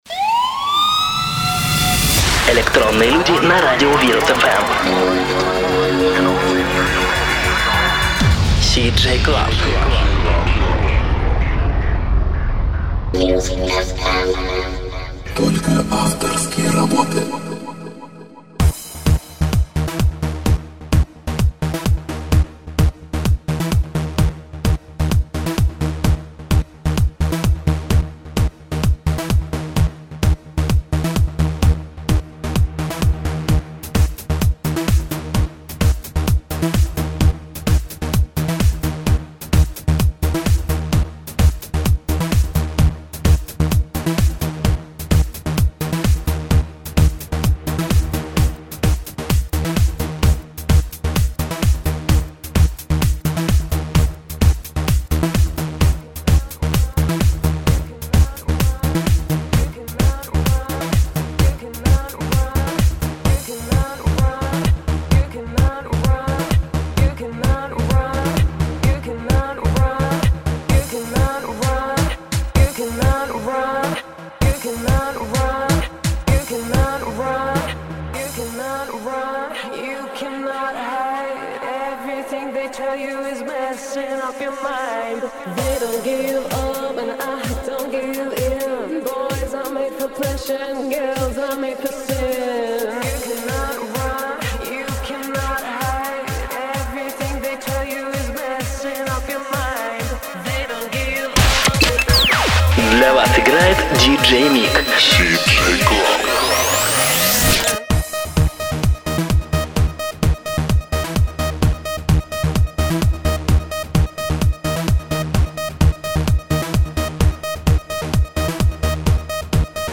транс